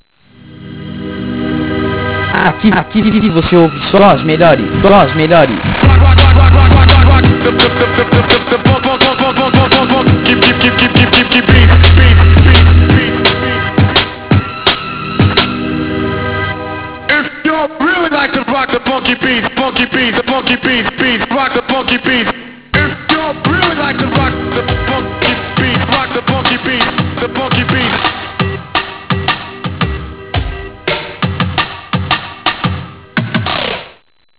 Aqui Algumas De Nossas Vinhetas e Chamadas
Todas Produzidas Pelos Dj's Da Rádio